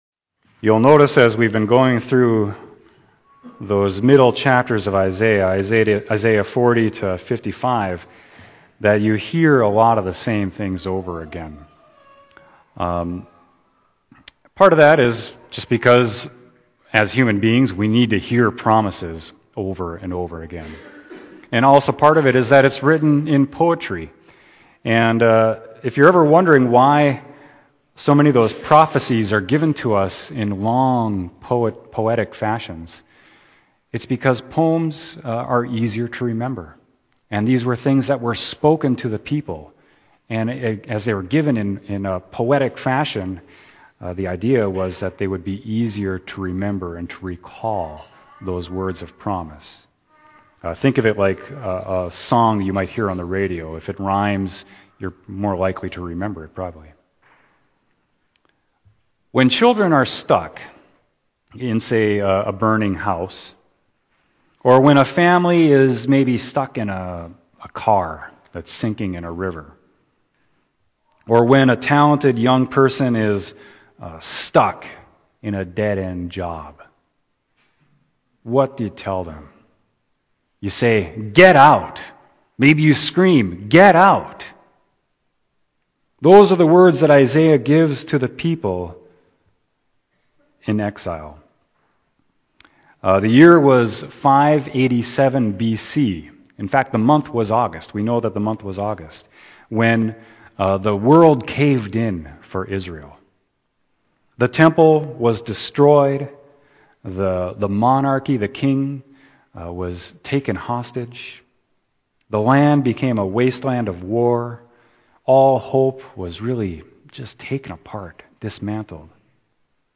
Sermon: Isaiah 48.17-22